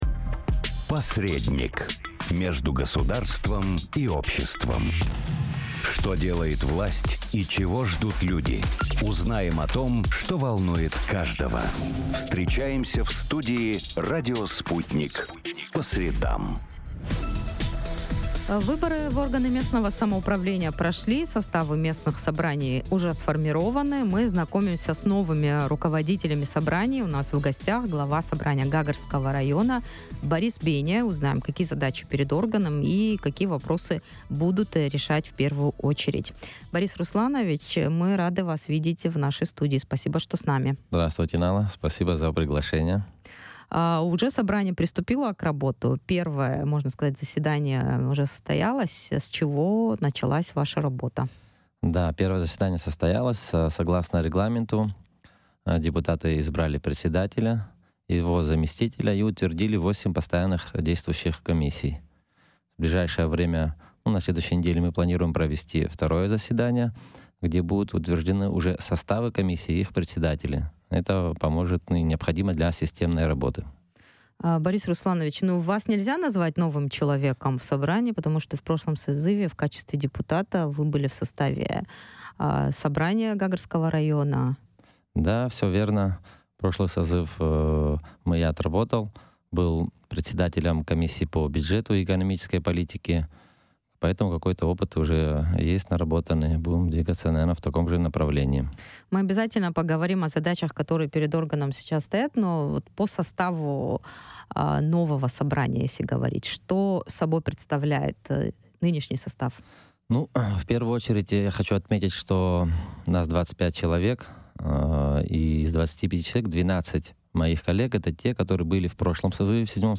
17 ноября состоялось первое заседание депутатов Собрания Гагрского района Vlll созыва, на котором председателем Собрания избрали Бориса Бения. В интервью радио Sputnik он рассказал, какие задачи стоят перед депутатами района.